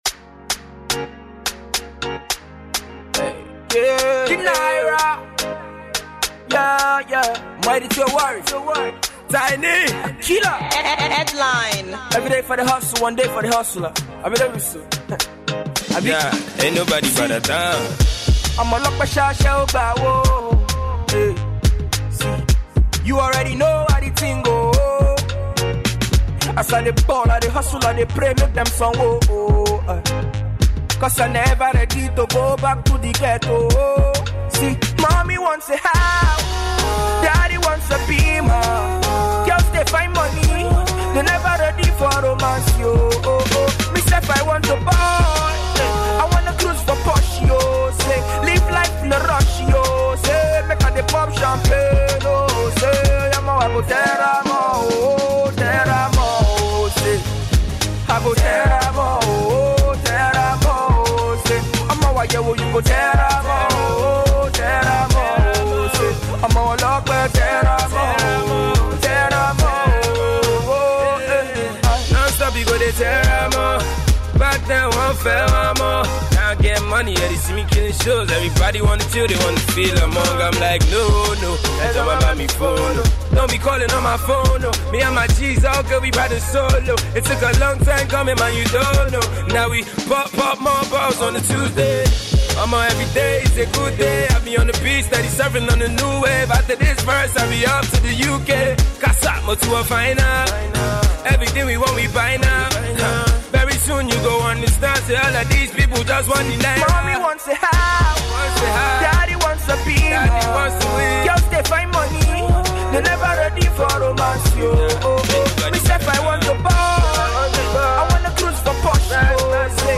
Afro-Pop single